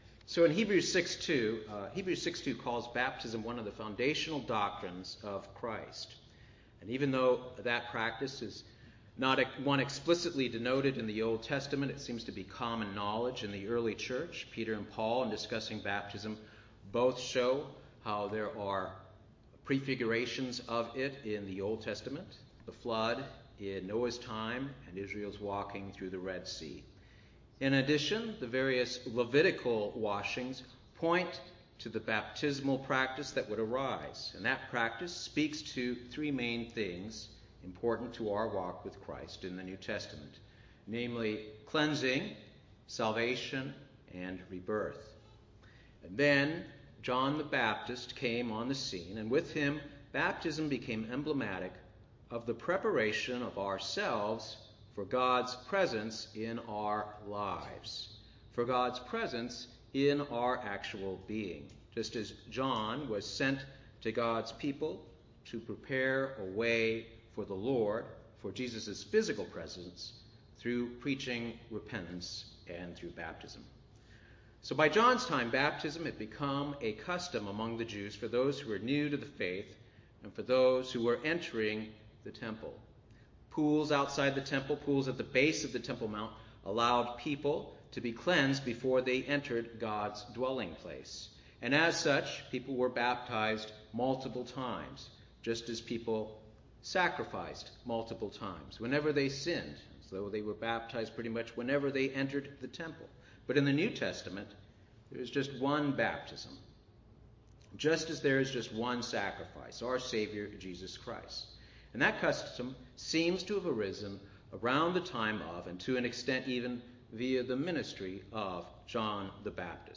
In a split-sermon before the combined congregations of Atlanta and Buford, GA